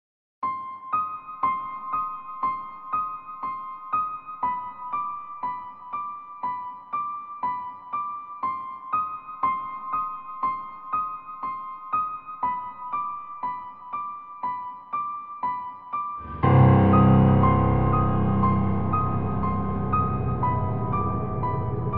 Жуткая музыка на пианино из фильмов ужасов